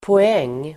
Uttal: [po'eng:]